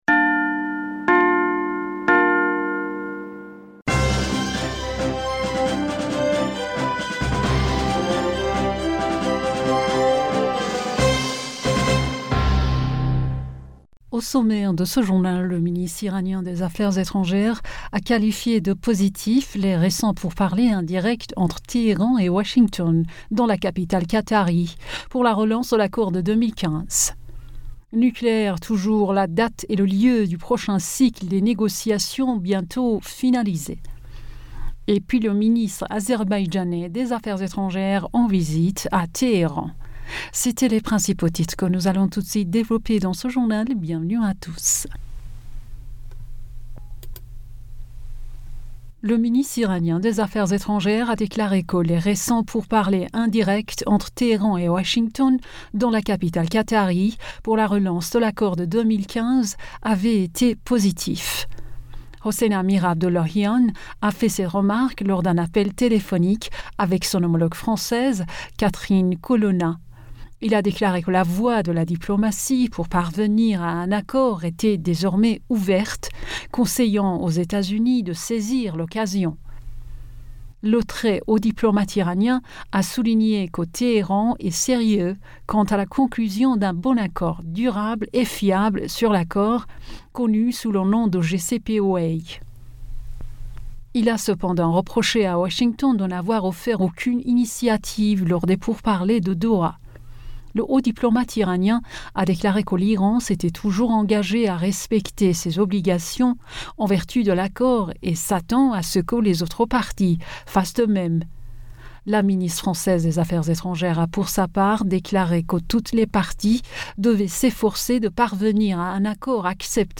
Bulletin d'information Du 04 Julliet